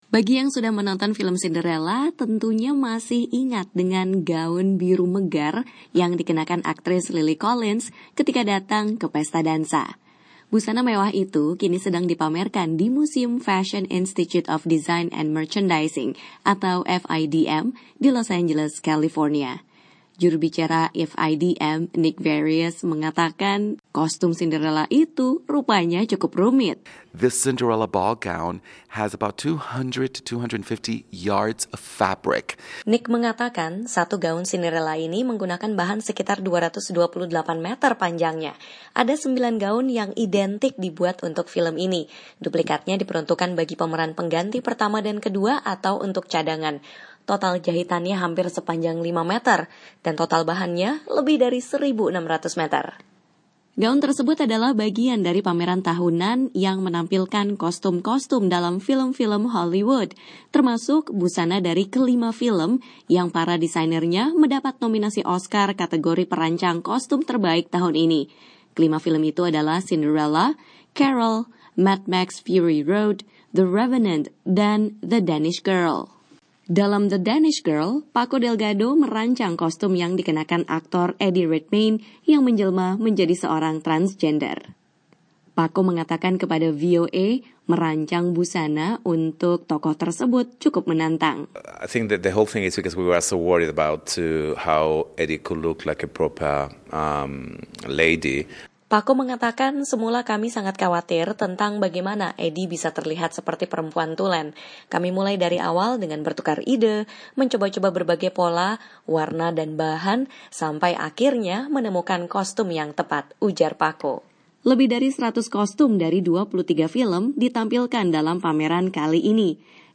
Lebih dari 100 kostum dari 23 film blockbuster dipamerkan di Museum Fashion Institute of Design and Merchandising (FIDM) di Los Angeles. Turut dipamerkan adalah kostum-kostum dari lima film yang meraih nominasi perancang busana terbaik. Wartawan VOA